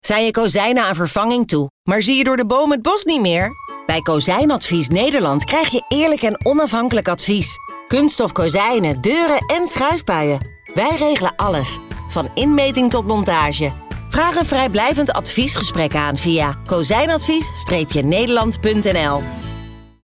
Radiocommercial-juli2025.mp3